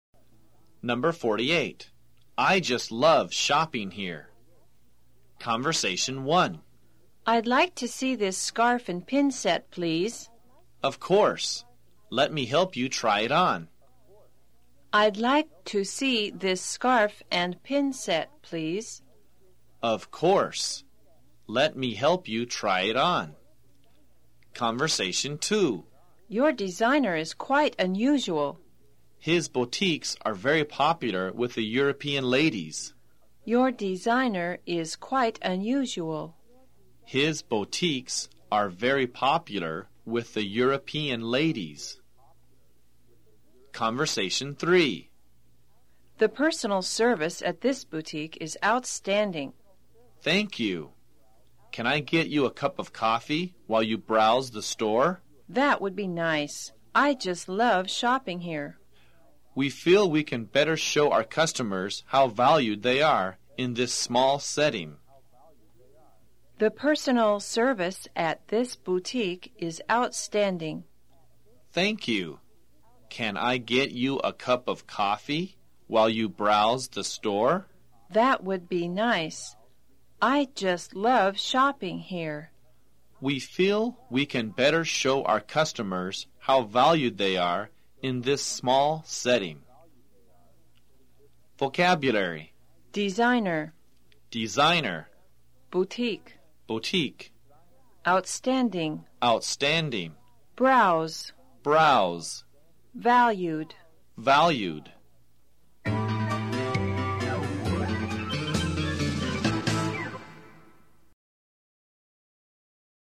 在线英语听力室快口说英语048的听力文件下载,快口说英语的每一句话都是地道、通行全世界的美国英语，是每天24小时生活中，时时刻刻都用得上的美语。